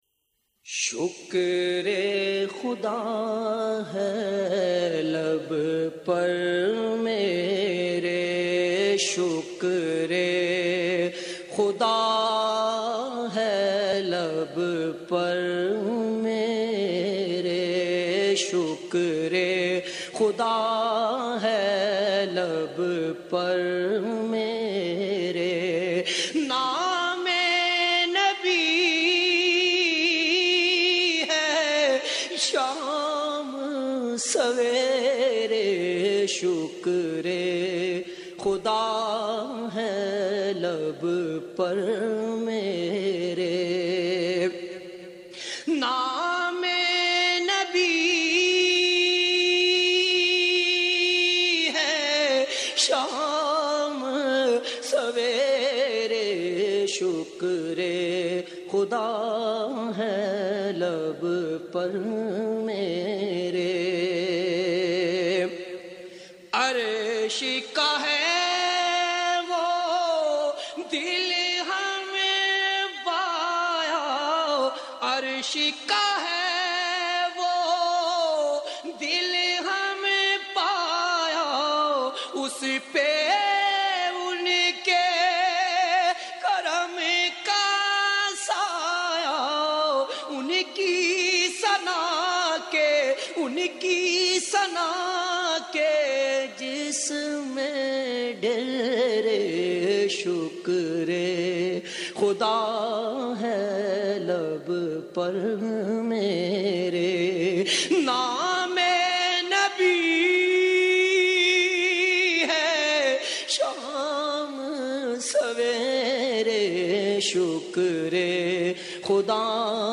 نعت رسول مقبول ص